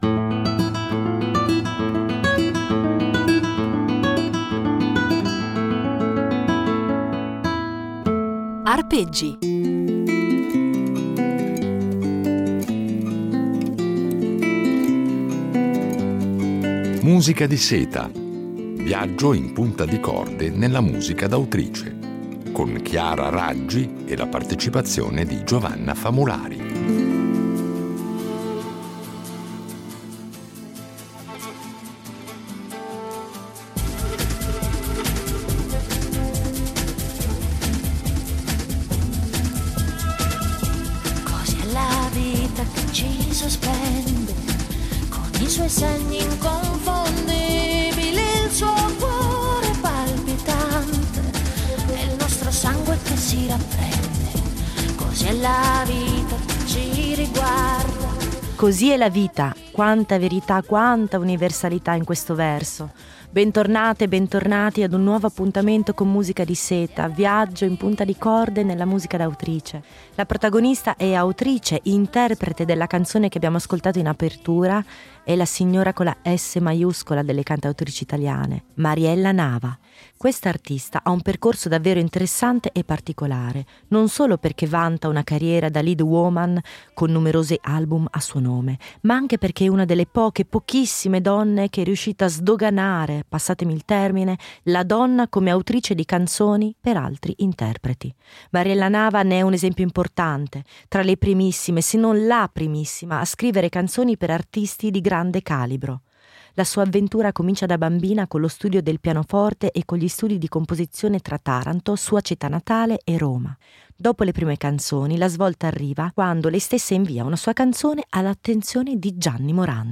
Una serie di Arpeggi impreziosita dalle riletture originali di un duo, ancora inedito, con la violoncellista